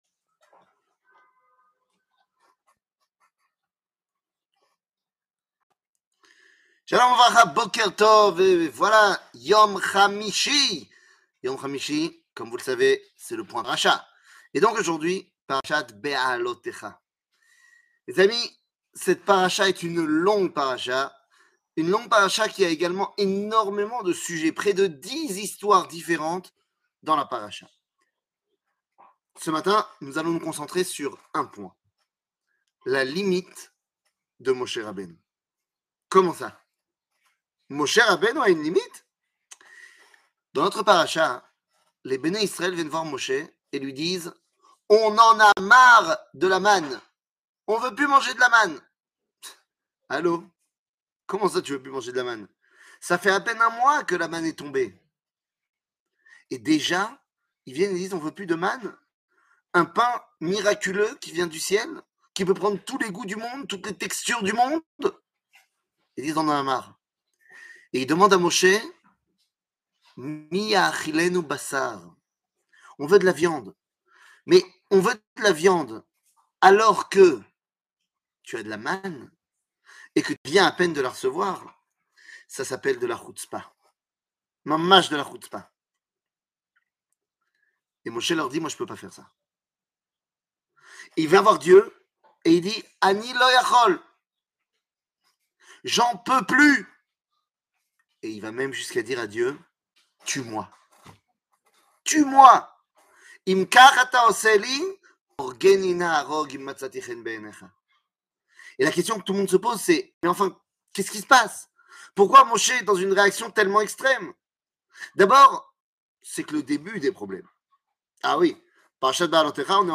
שיעור מ 09 יוני 2022